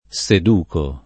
sedurre [ S ed 2 rre ] v.